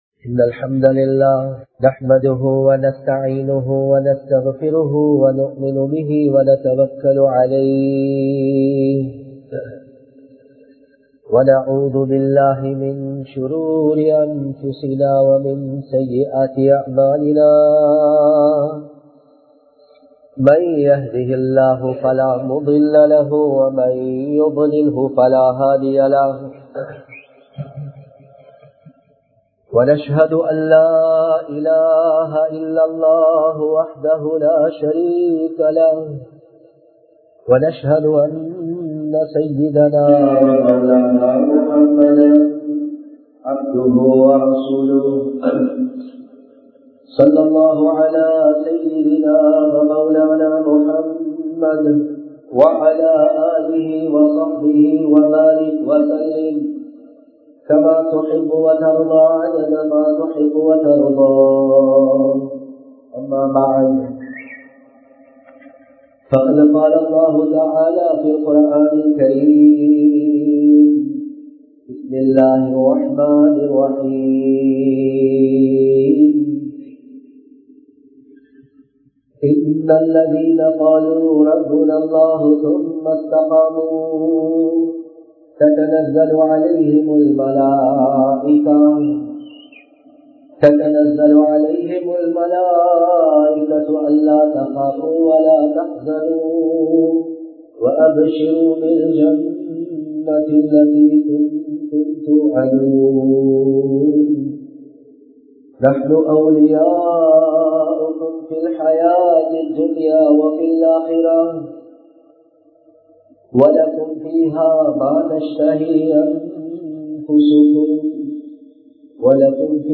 துல்ஹிஜ்ஜாவின் சிறப்புகள் | Audio Bayans | All Ceylon Muslim Youth Community | Addalaichenai